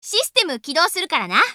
System Voice
Natsuki AsakuraStartup sound